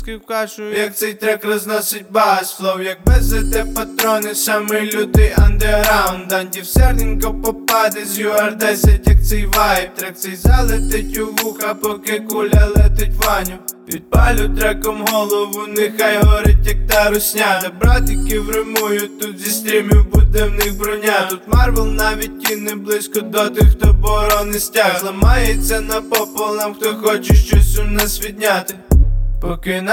Жанр: Рэп и хип-хоп / Иностранный рэп и хип-хоп / Украинские